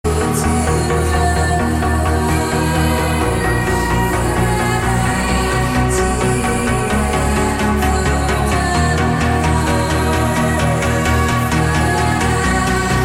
R&B/Soul Ringtones